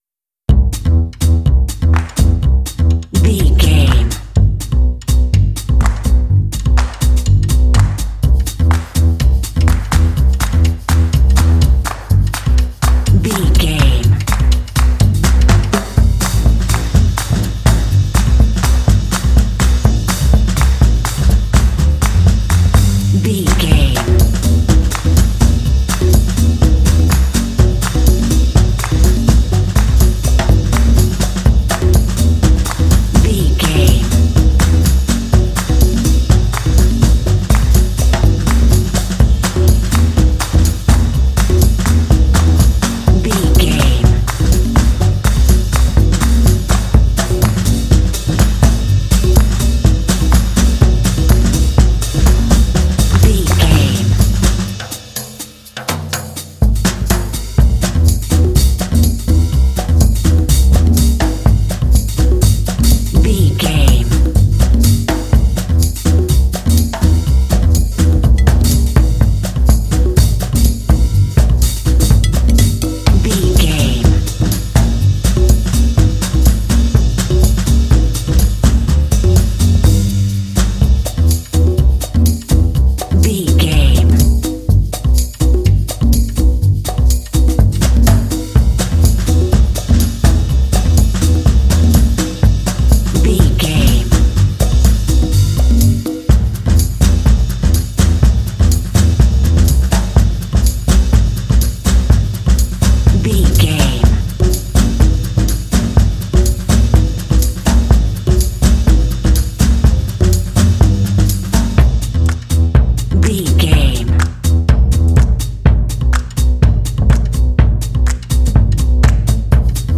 Ionian/Major
bouncy
energetic
cheerful/happy
groovy
double bass
percussion
drums
60s
jazz
bossa